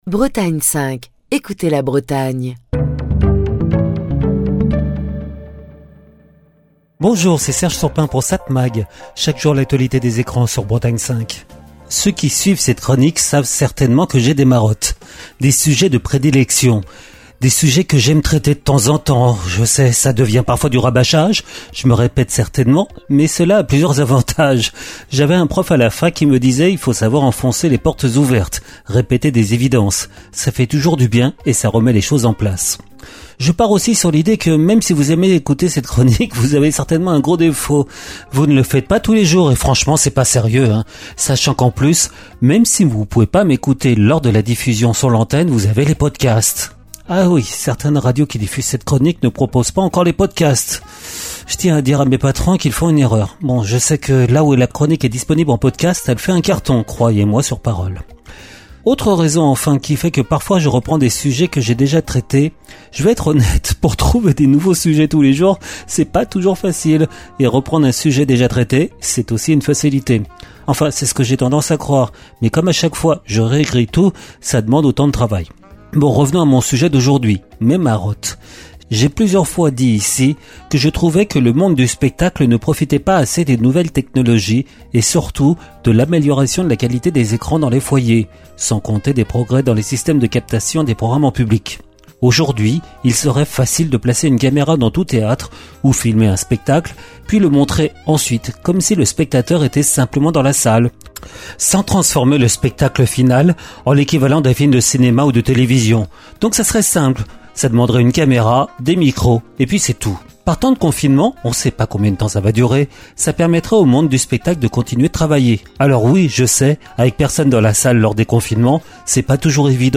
Chronique du 7 avril 2025.